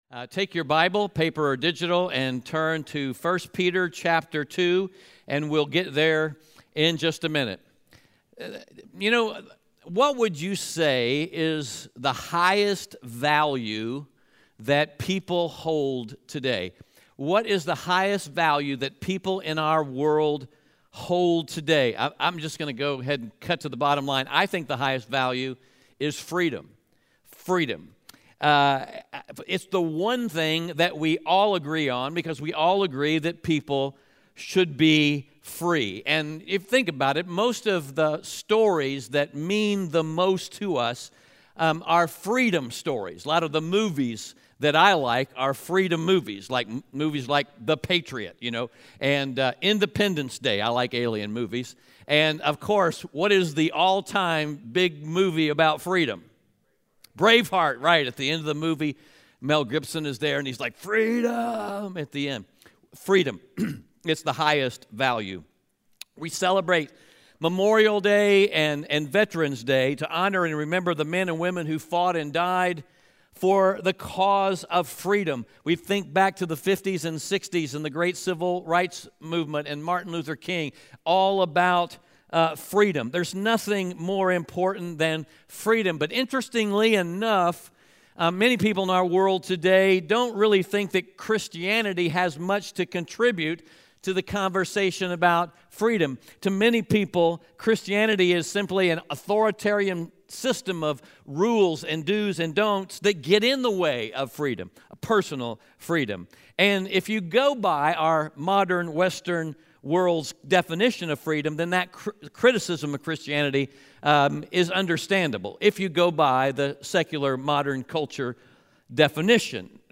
Audio Sermon Notes (PDF) Ask a Question The highest value in our world today is freedom.